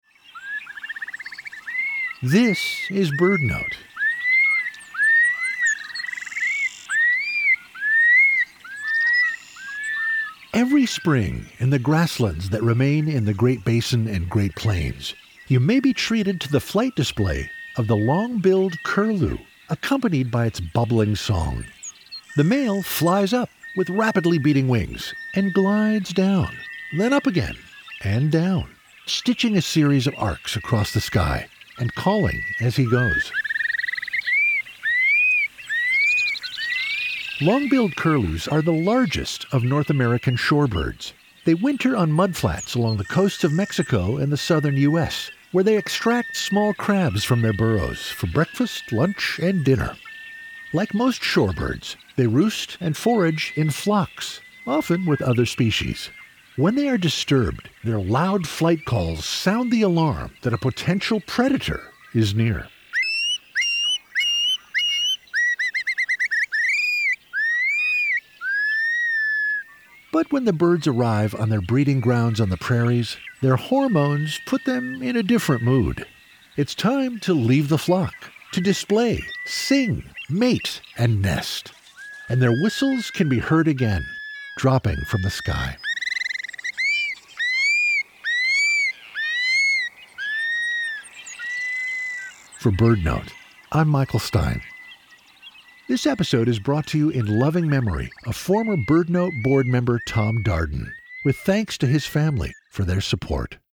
Long-billed Curlews are the largest shorebirds in North America. In his courtship display, the male Long-billed Curlew flies a series of arcs across the sky, calling as he goes.
When the flock is disturbed, the curlews’ loud flight calls sound the alarm that a potential predator is near.